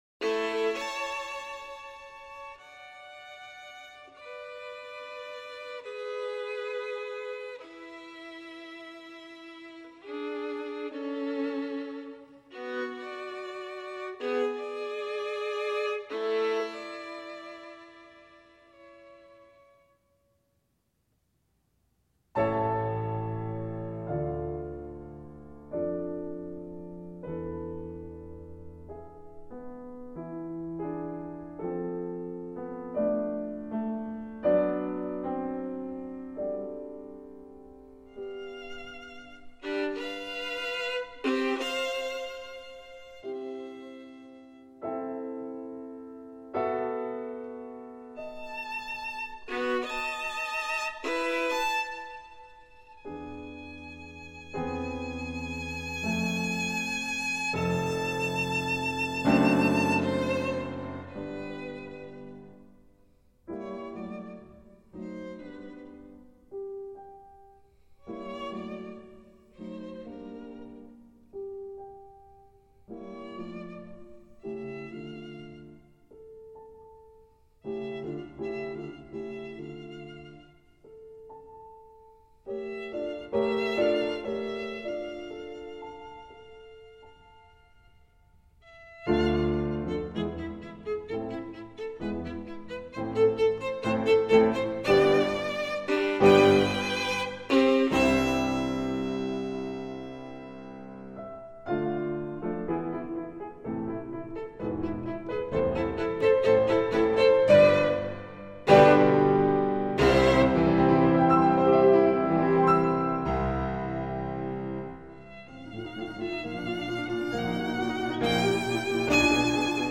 Beethoven published this violin sonata in 1802, after Symphony No. 2 and Piano Concerto No. 3.
Here is the Stuffed Animal Players rendition of the Adagio sostenuto; Presto
beethovenviolinsonata9kreutzermvmt1.mp3